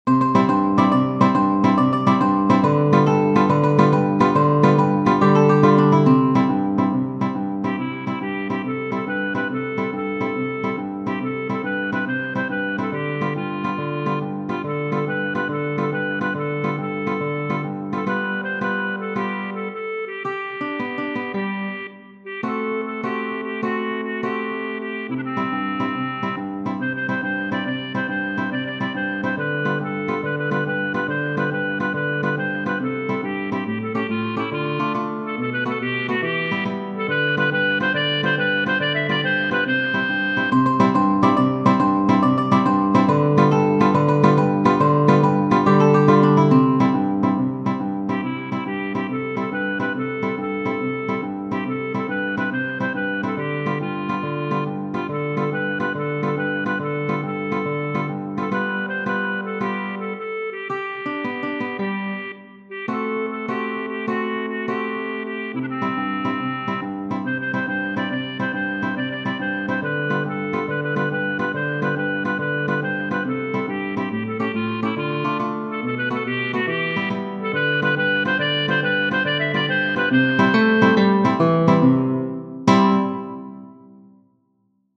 Fassone, V. Genere: Napoletane Testo di Giuseppe Capaldo Vurría sapé pecché si mme vedite, facite sempe 'a faccia amariggiata...